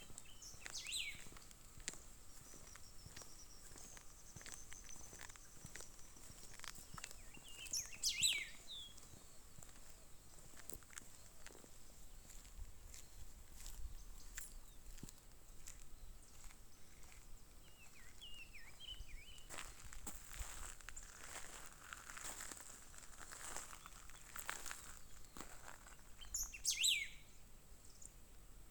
Golden-billed Saltator (Saltator aurantiirostris)
Location or protected area: Amaicha del Valle
Condition: Wild
Certainty: Observed, Recorded vocal